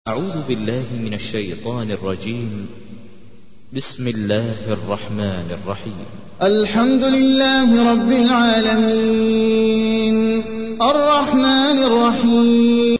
تحميل : 1. سورة الفاتحة / القارئ احمد العجمي / القرآن الكريم / موقع يا حسين